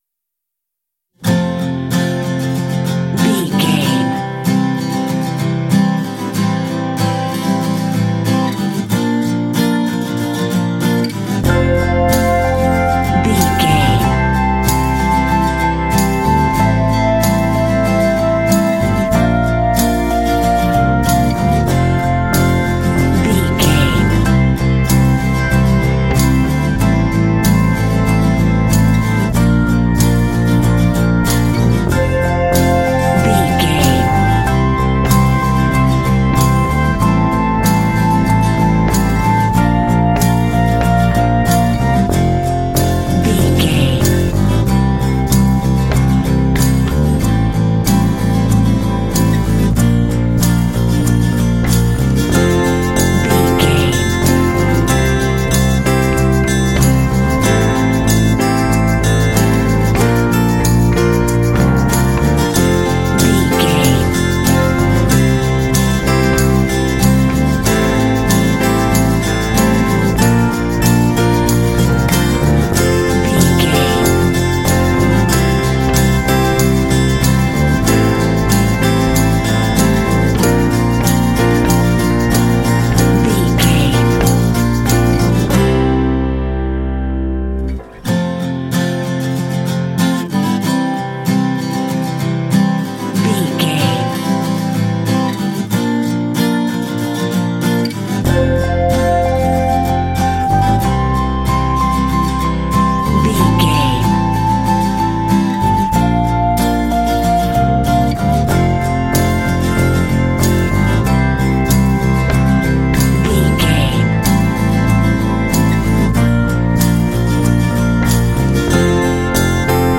Uplifting
Ionian/Major
optimistic
driving
sentimental
acoustic guitar
bass guitar
synthesiser
electric organ
percussion
alternative rock
indie